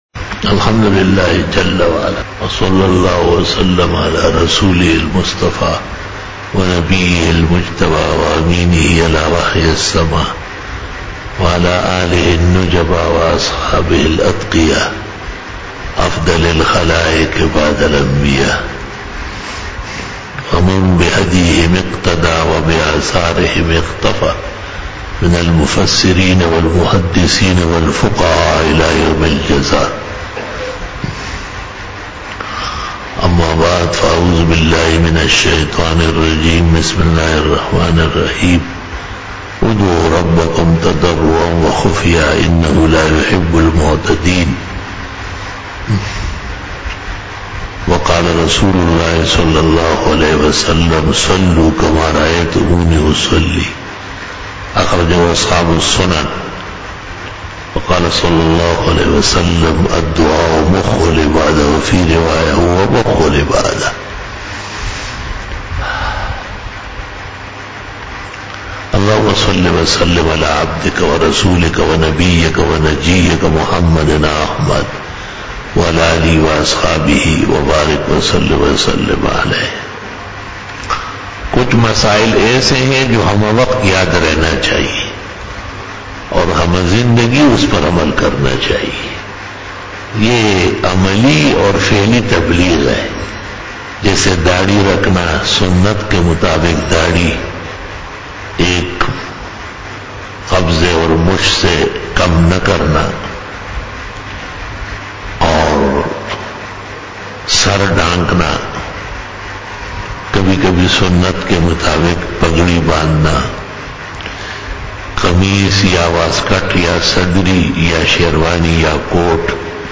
48 BAYAN E JUMA TUL MUBARAK (29 November 2019) (01 Rabi Ul Sani 1441H)